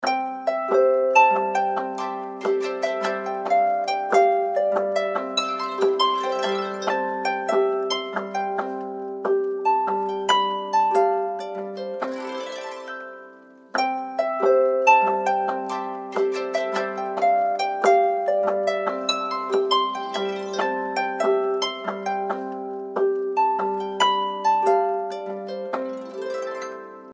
Рингтоны без слов , Спокойные рингтоны
Арфа , Инструментальные